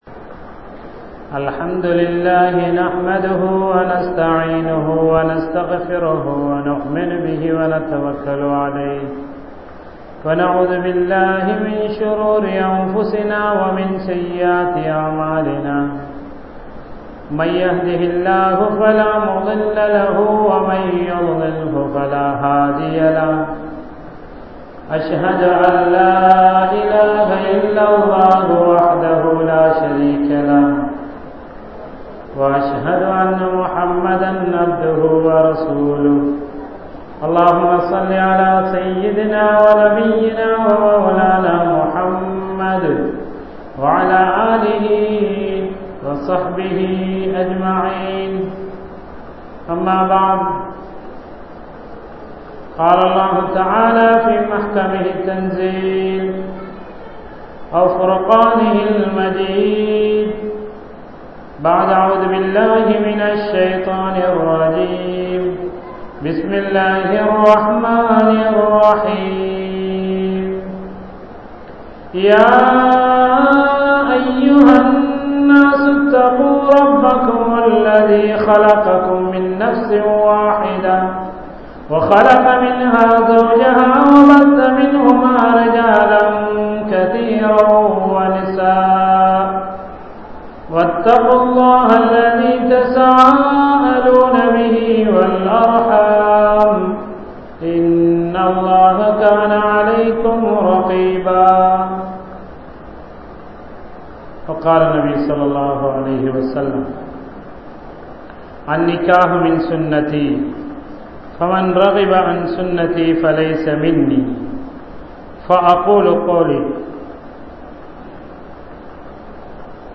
Nikkah Ethatku? (நிக்காஹ் எதற்கு?) | Audio Bayans | All Ceylon Muslim Youth Community | Addalaichenai
Masjithur Ravaha